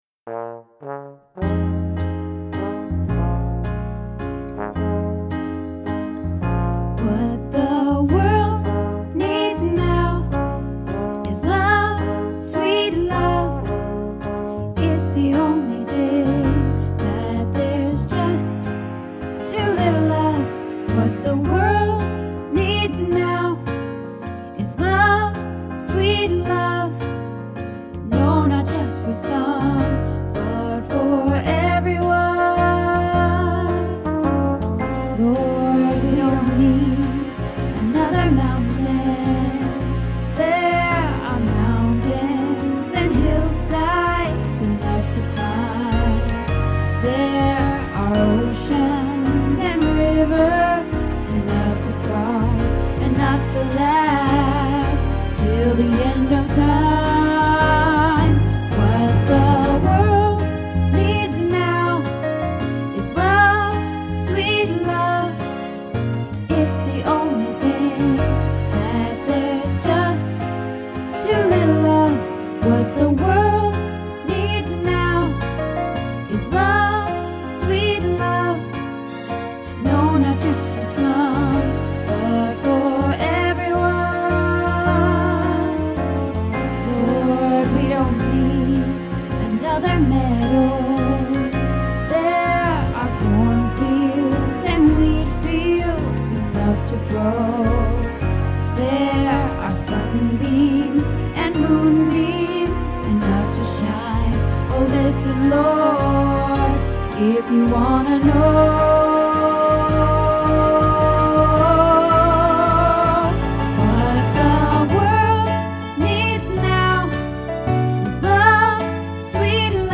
What the World Needs Now - Alto